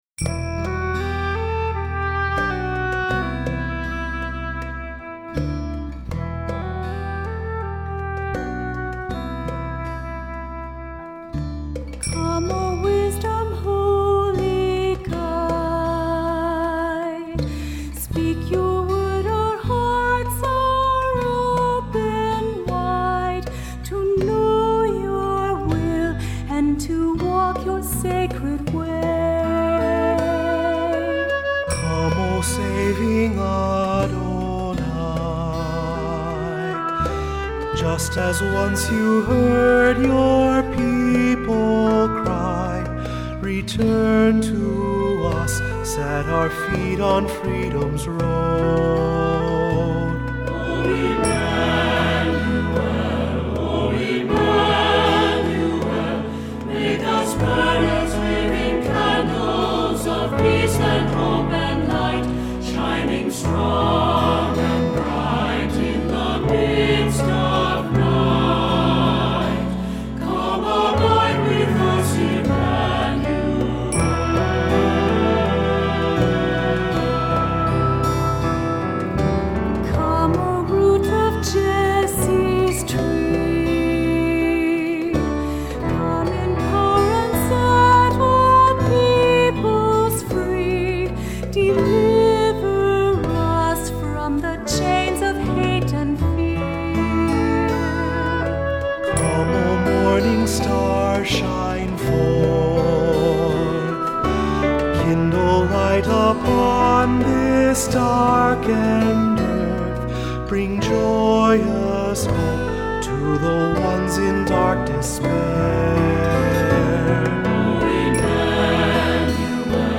Accompaniment:      Keyboard, C Instrument
Music Category:      Choral